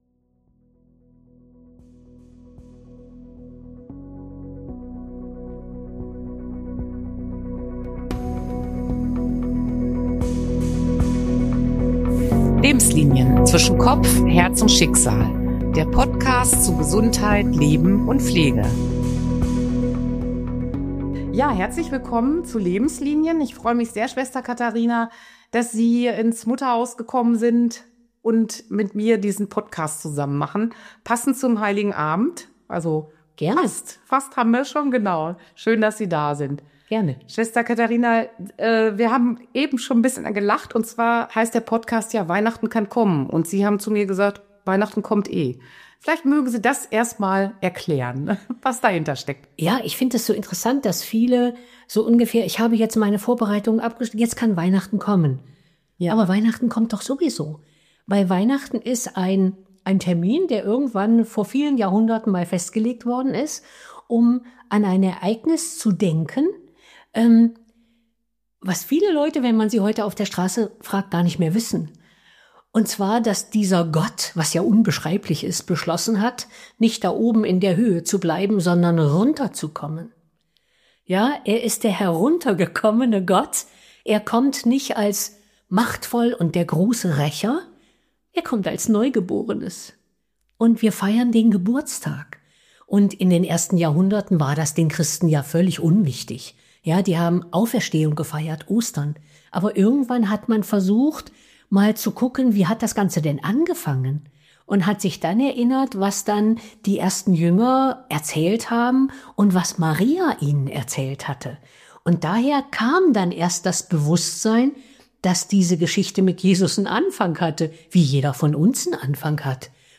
Im Gespräch erinnert sie sich auch an ihre Kindheit in der Familie und die Bedeutung der Weihnacht.